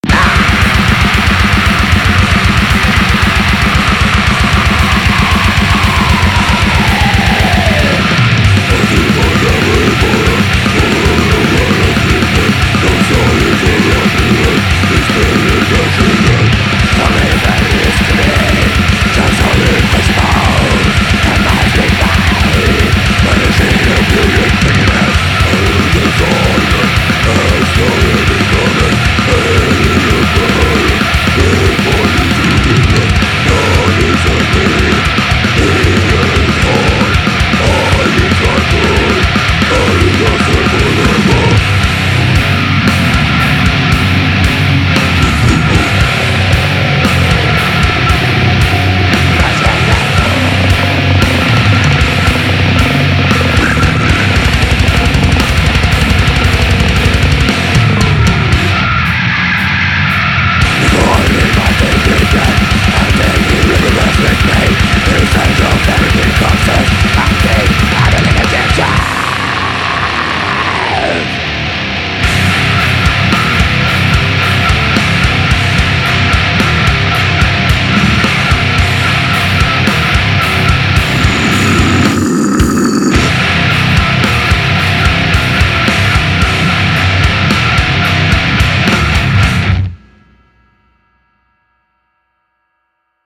Ének